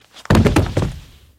Звуки падения
Звук: человек потерял сознание и грохнулся на деревянный пол